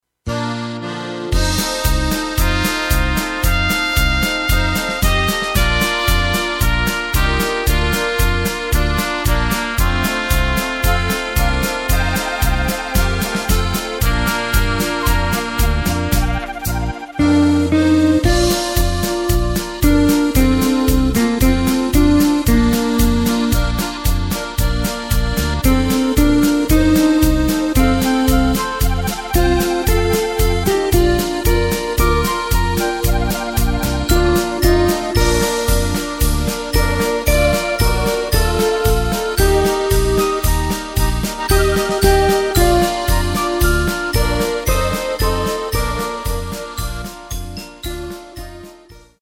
Takt:          4/4
Tempo:         113.50
Tonart:            Bb
Marsch-Lied!